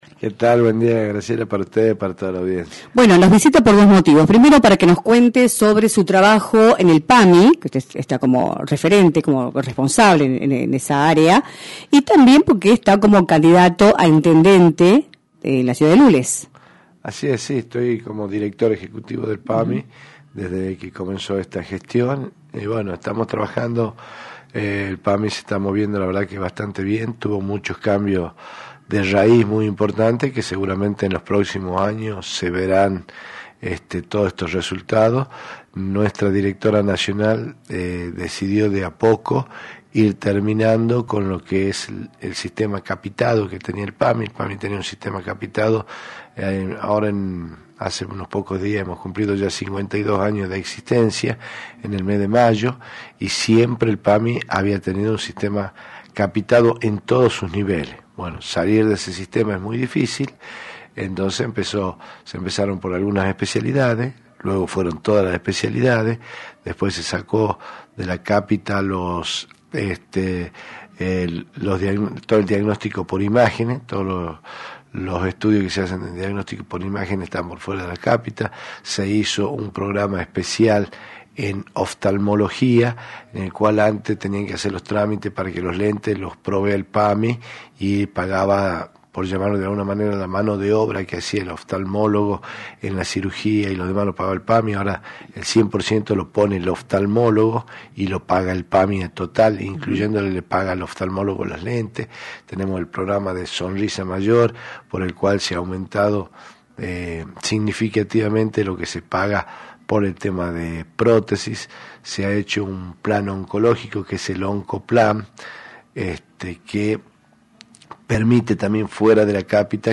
César Kelo Dip, Director Ejecutivo del PAMI y candidato a Intendente de Lules, visitó los estudios de «Libertad de Expresión», por la 106.9, para abordar el escenario político de la provincia de cara a las elecciones del 11 de junio y para analizar la gestión sanitaria y administrativa del PAMI en Tucumán.